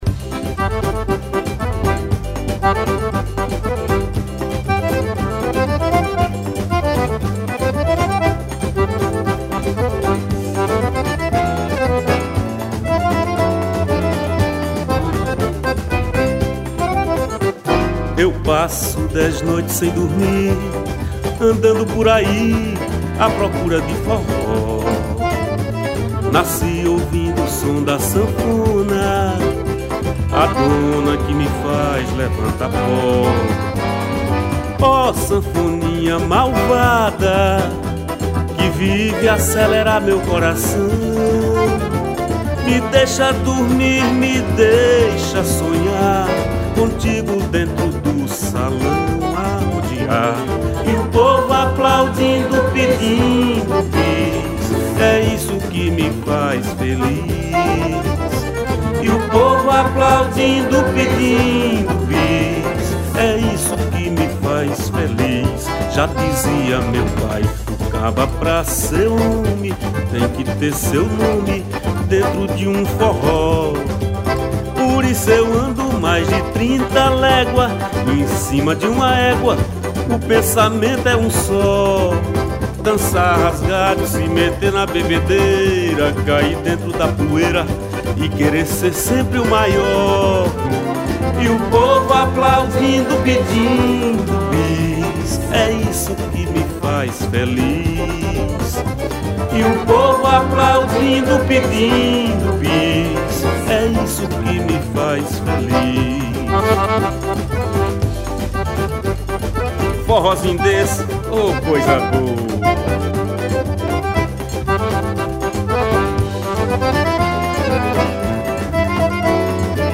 1509   02:58:00   Faixa:     Forró
Acoordeon
Triângulo
Guitarra
Baixo Elétrico 6
Cavaquinho
Bateria
Zabumba, Pandeiro